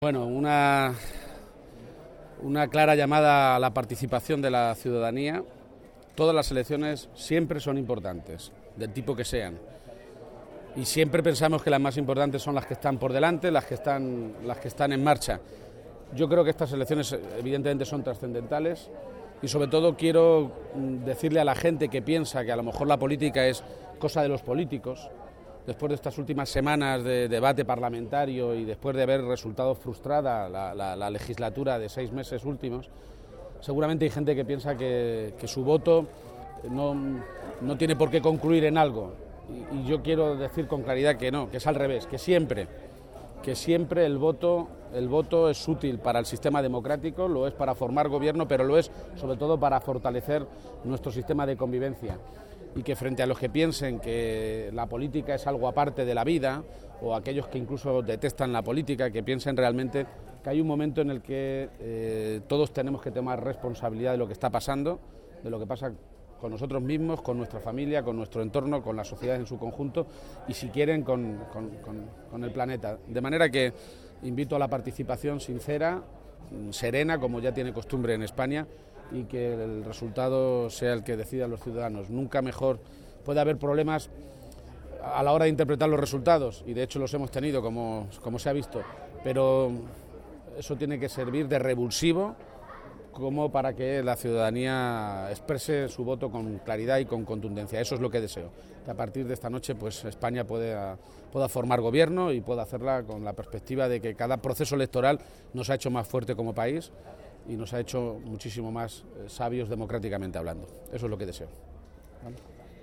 García-Page que ha realizado estas manifestaciones tras ejercer su derecho al voto en el colegio “Ciudad de Nara” de Toledo, ha indicado que si todas las elecciones son siempre importantes, estás lo son aún más.
Cortes de audio de la rueda de prensa